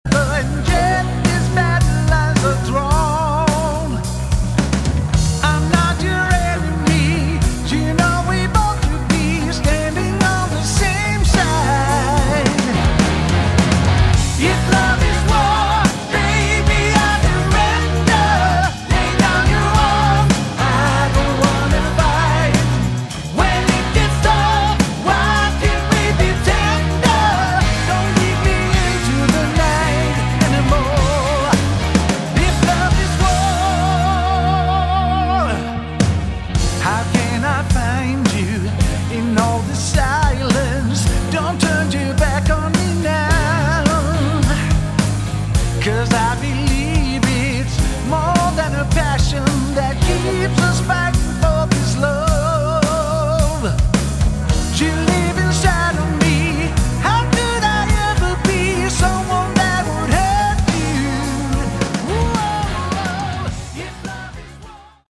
Category: AOR / Melodic Rock